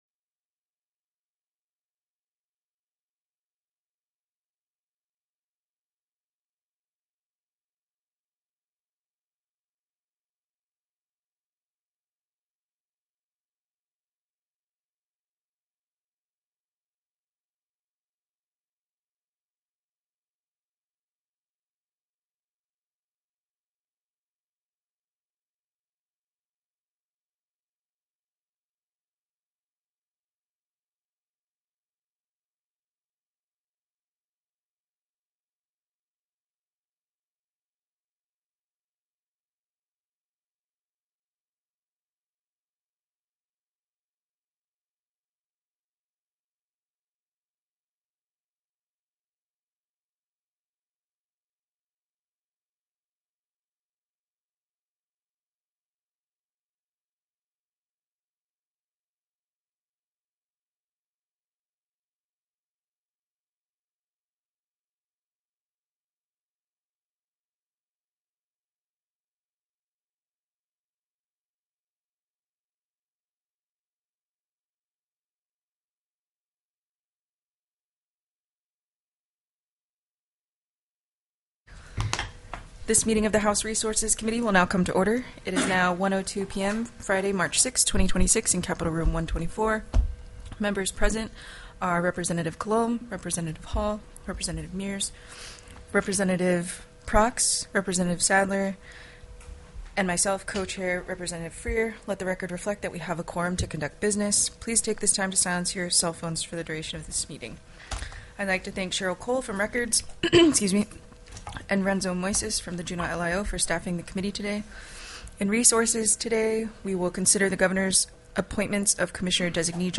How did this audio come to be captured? TELECONFERENCED